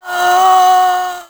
c_zombif1_atk1.wav